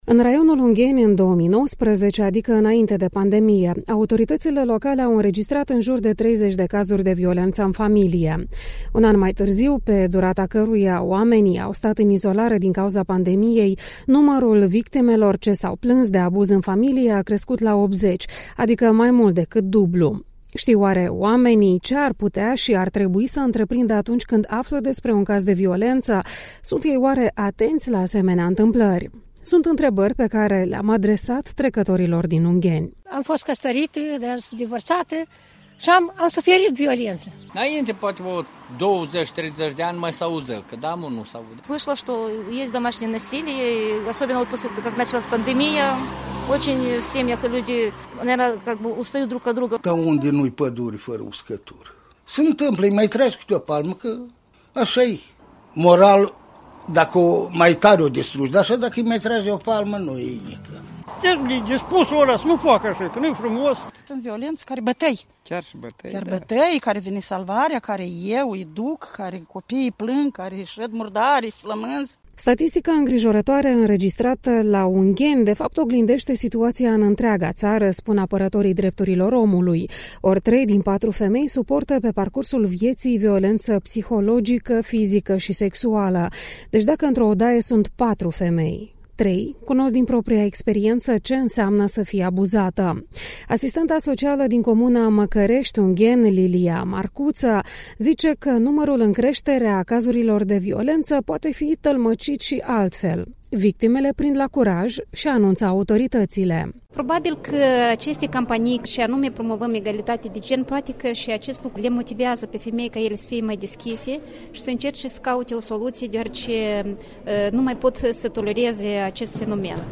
Sunt întrebări adresate trecătorilor din Ungheni: